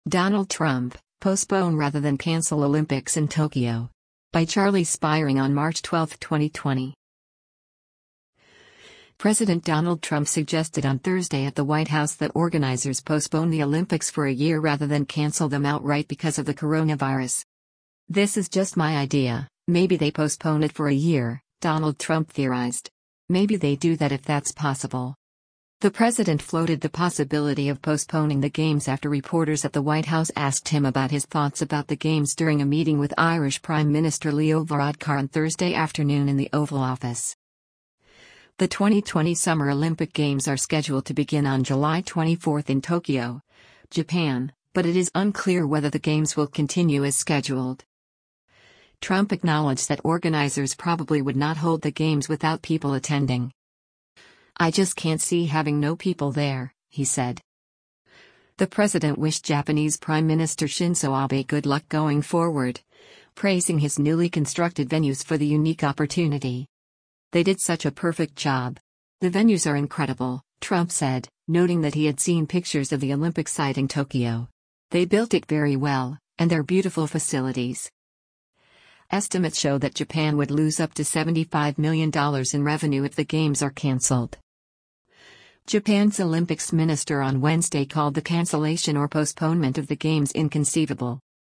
The president floated the possibility of postponing the games after reporters at the White House asked him about his thoughts about the games during a meeting with Irish Prime Minister Leo Varadkar on Thursday afternoon in the Oval Office.